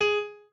pianoadrib1_18.ogg